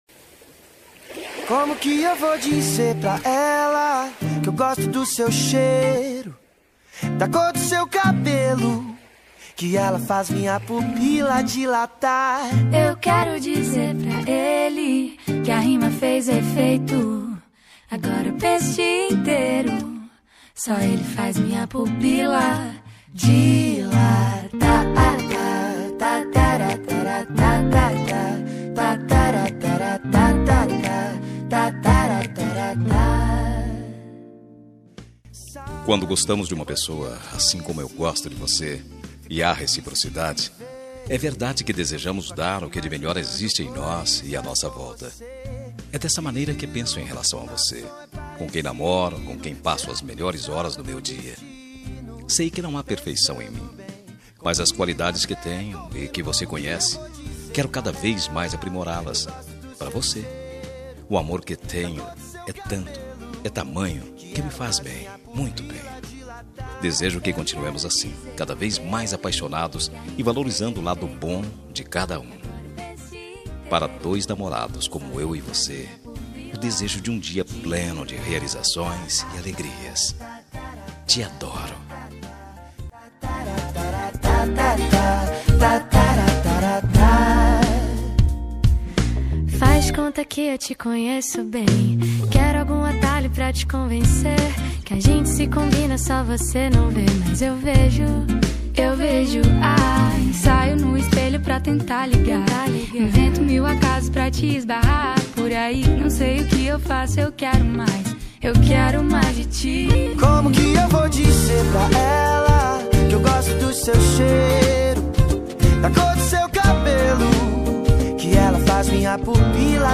Dia dos Namorados – Para Namorada – Voz Masculina – Cód: 6870